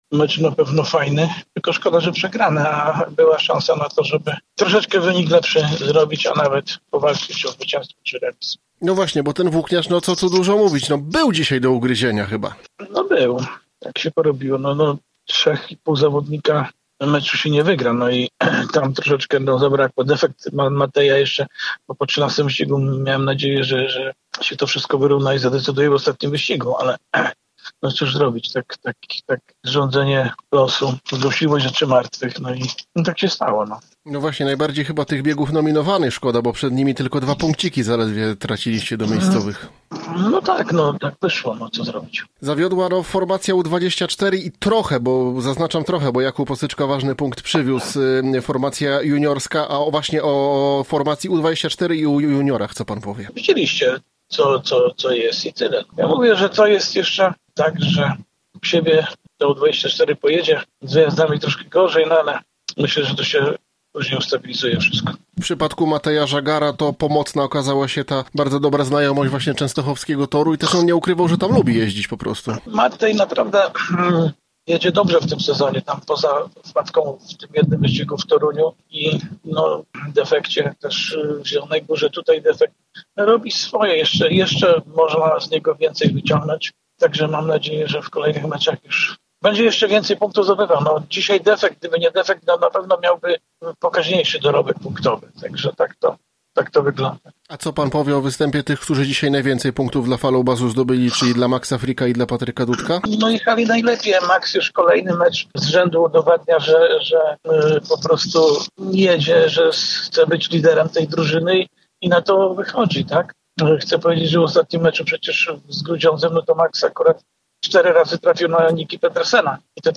Cała rozmowa ze szkoleniowcem poniżej: